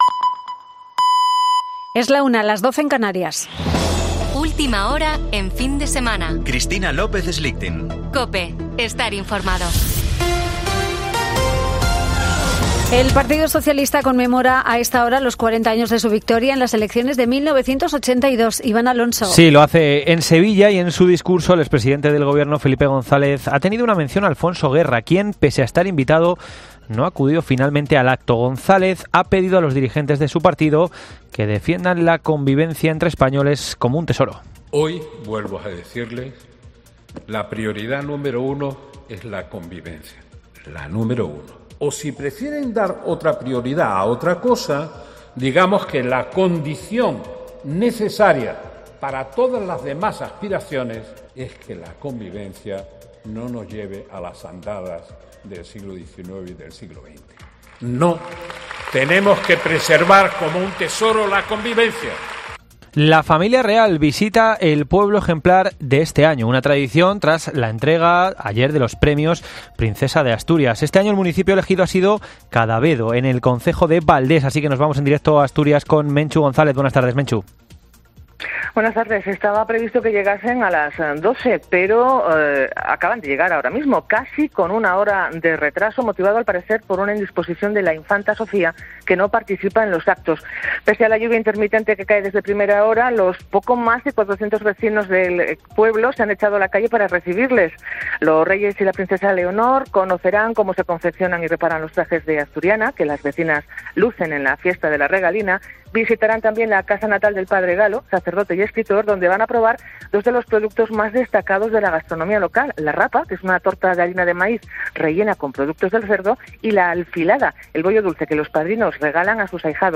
Boletín de noticias de COPE del 29 de octubre de 2022 a las 13.00 horas